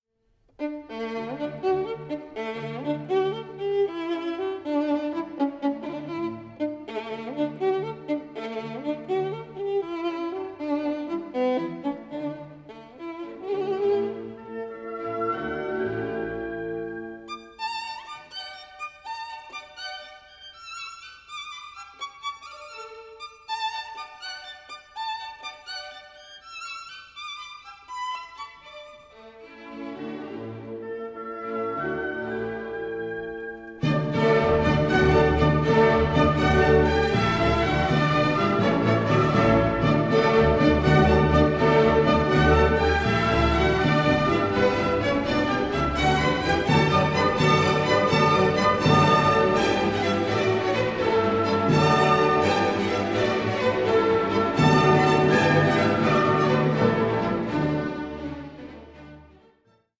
(48/24, 88/24, 96/24) Stereo  14,99 Select
vivid, multi-channel original sound
in compelling and pristine multi-channel sound.